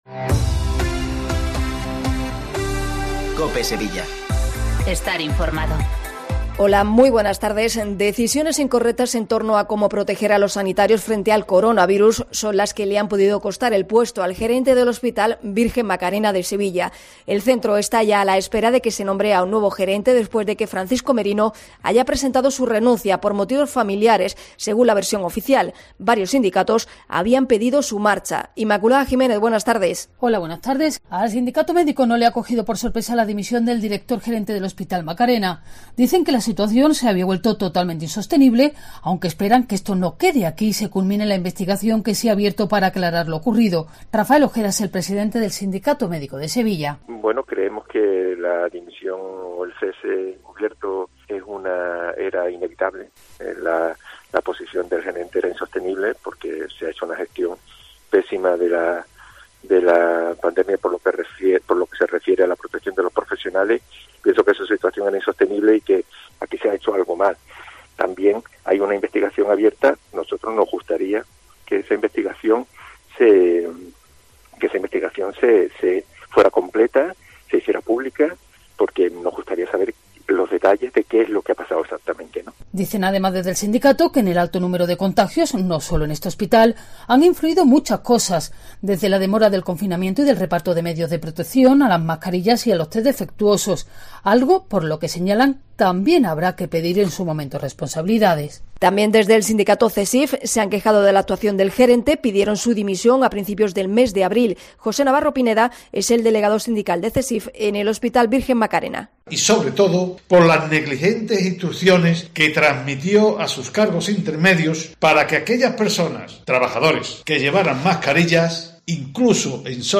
Toda la actualidad de Sevilla 14.20 informativos 13 MAYO 2020